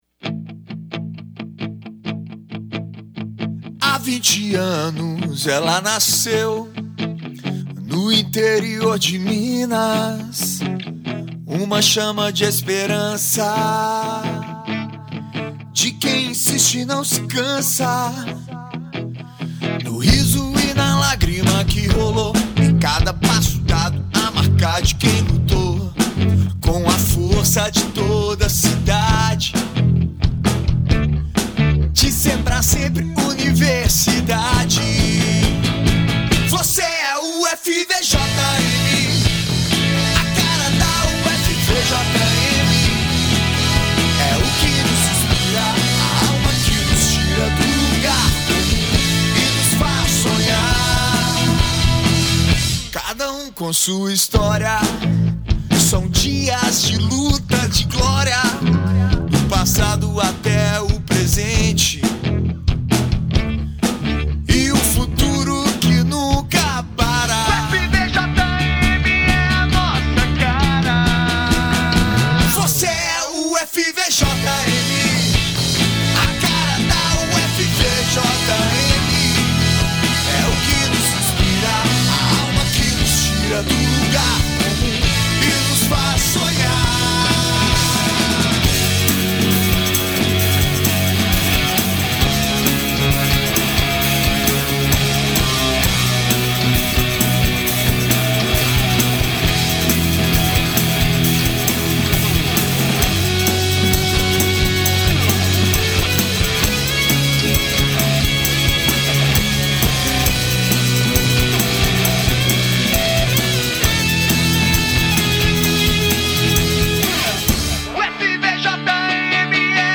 Com uma melodia envolvente e uma letra inspiradora